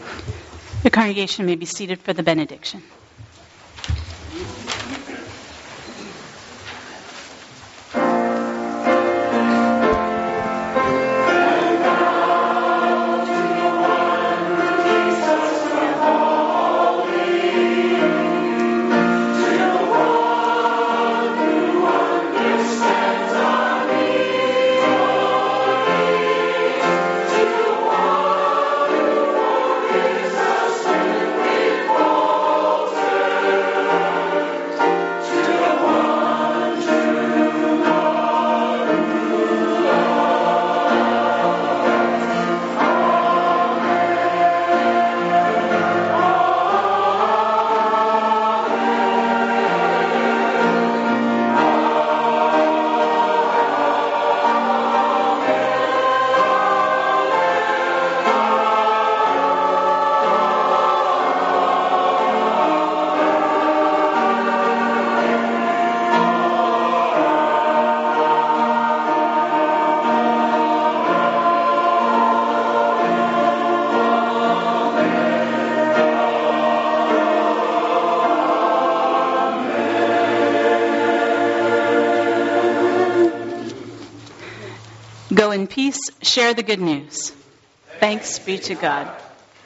LISTEN • 11 Nov 2018 • Benediction • Chancel Choir • 1:30